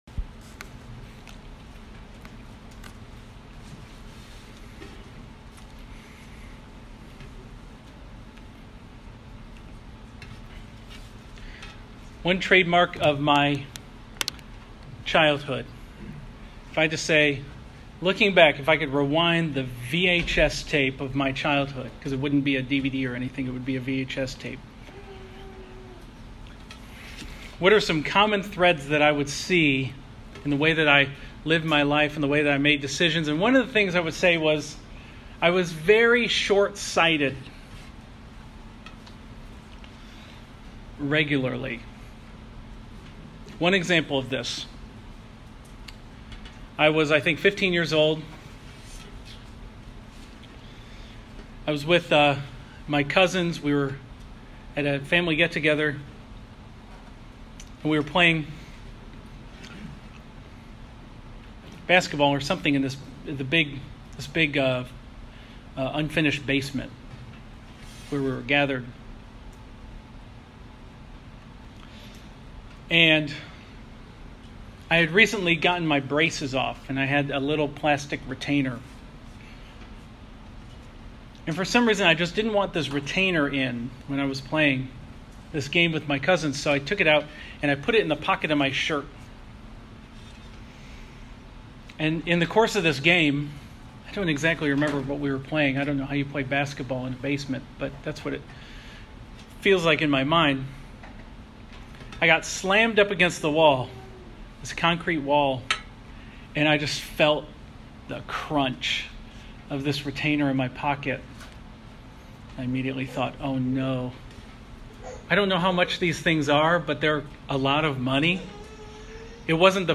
Download Audio Home Resources Sermons Resting and Pursuing in the Gospel